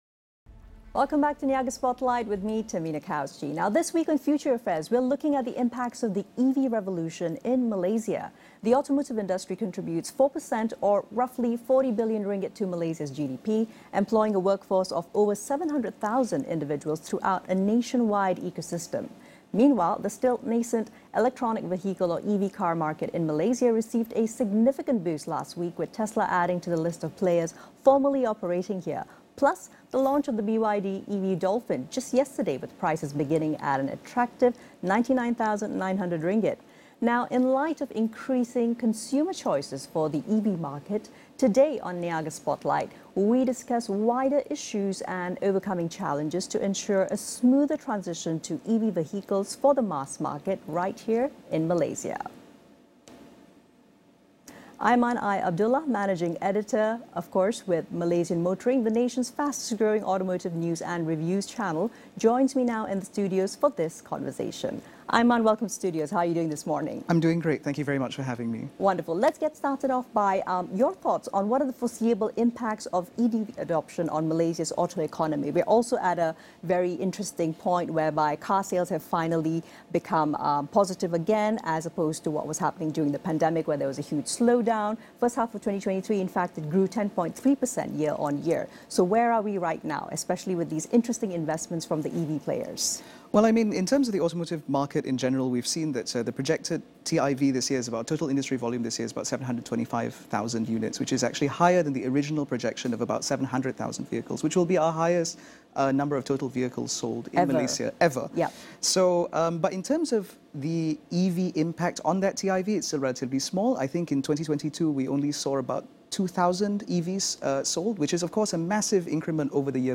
An insightful discussion on the future of EV adoption in Malaysia's auto economy, discovering the Future of EVs in Malaysia, from Charging Infrastructure to Local Development.